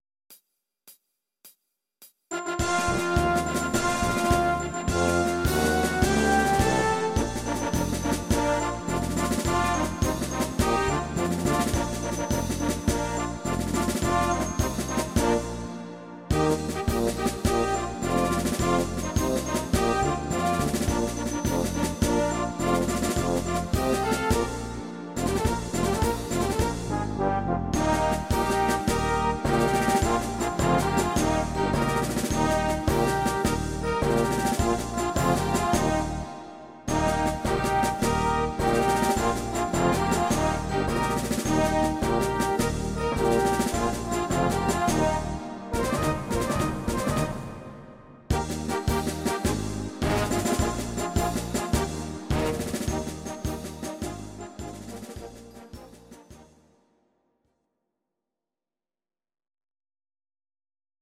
These are MP3 versions of our MIDI file catalogue.
Your-Mix: Jazz/Big Band (731)